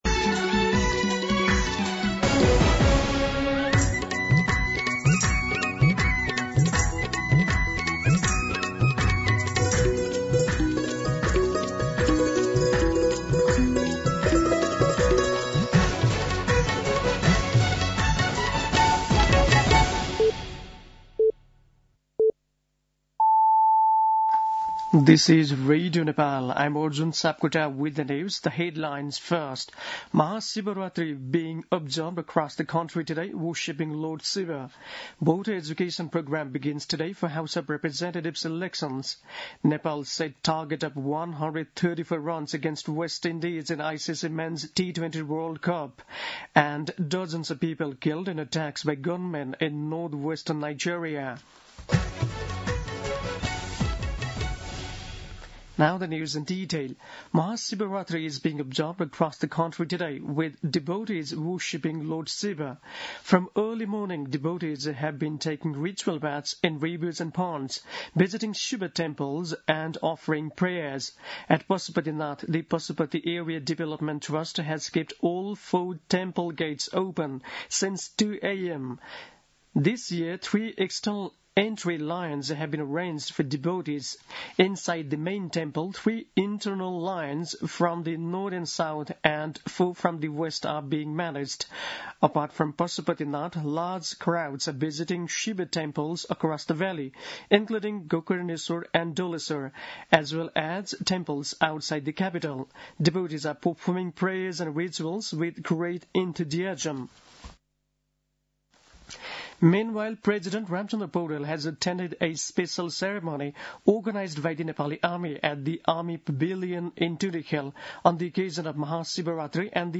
दिउँसो २ बजेको अङ्ग्रेजी समाचार : ३ फागुन , २०८२
2pm-English-News-11-03.mp3